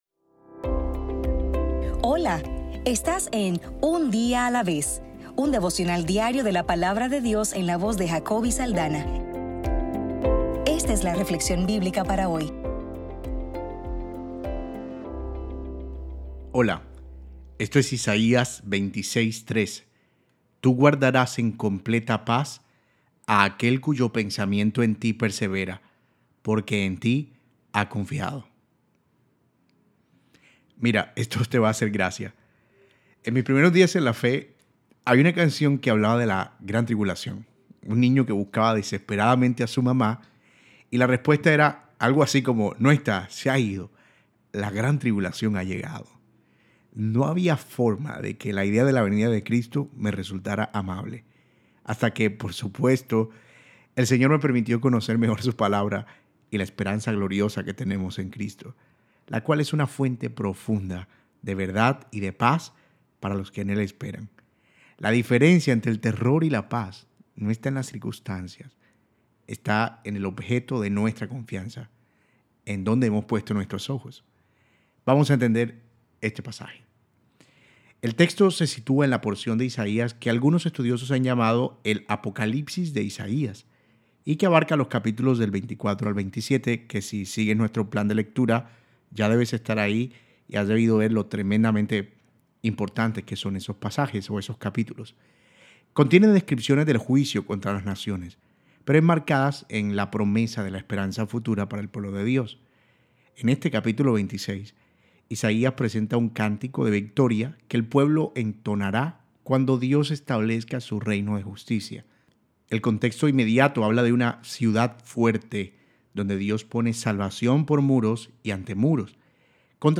Devocional para el 25 de mayo